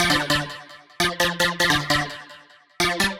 Index of /musicradar/future-rave-samples/150bpm